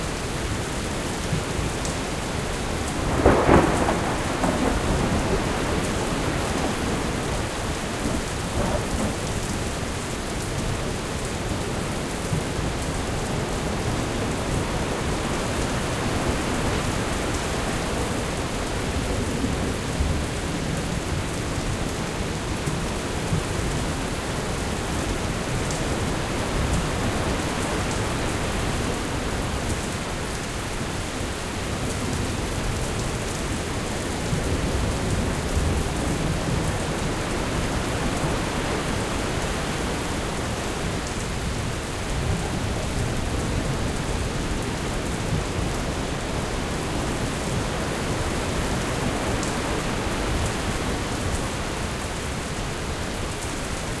Mayak_night.ogg